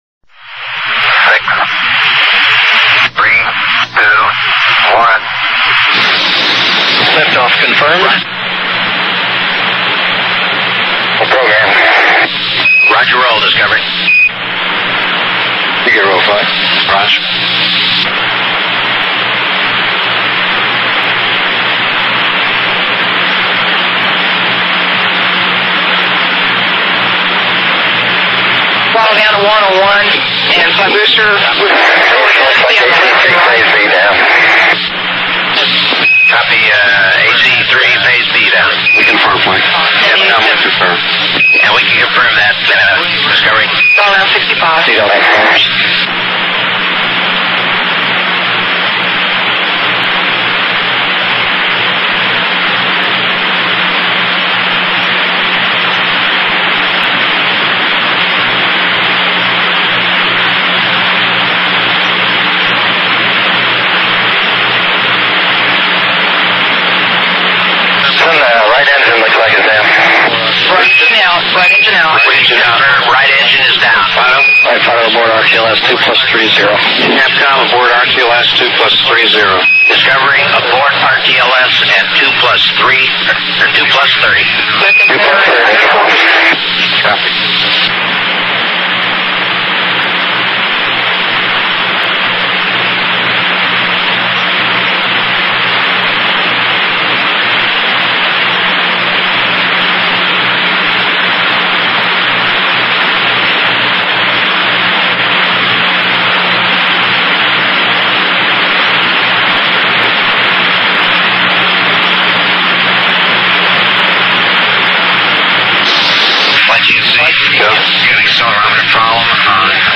Space Shuttle RTLS Launch Abort Simulation - Discovery STS-26 SIM.mp3